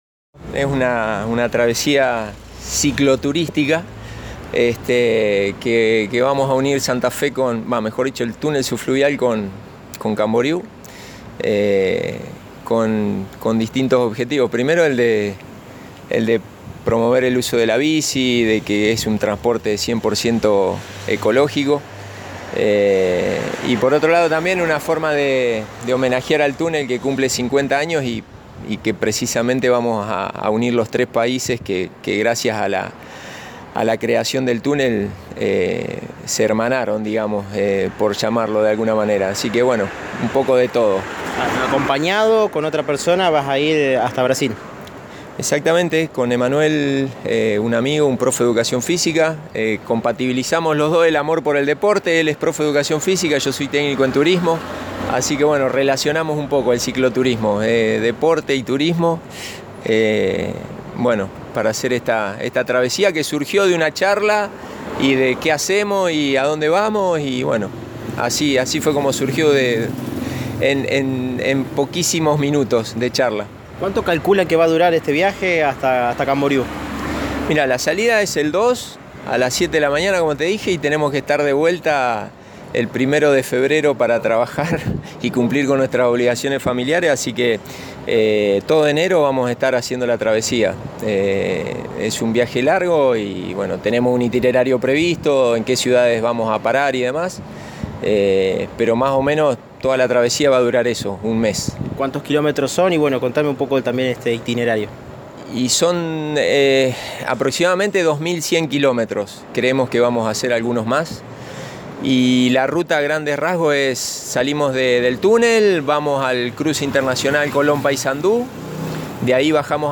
Antes de comenzar la travesía, uno de los ciclistas visitó los estudios de Radio EME.